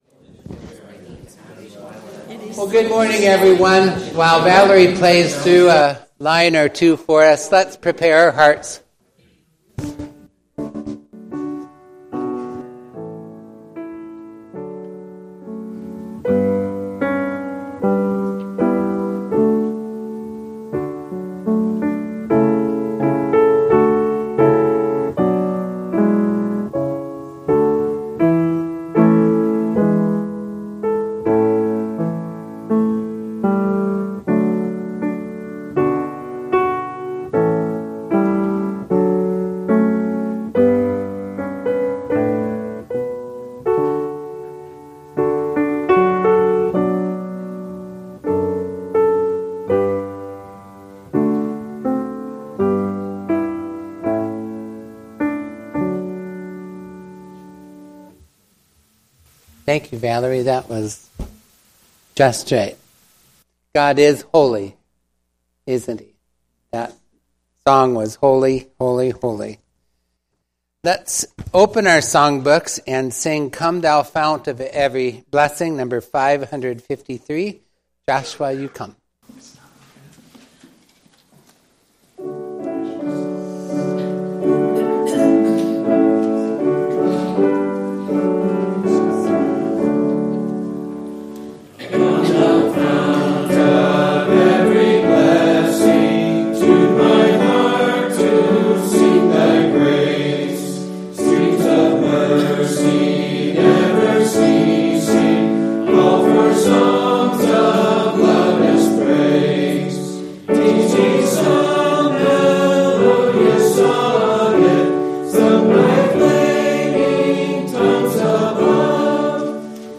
This Sunday’s Sermon: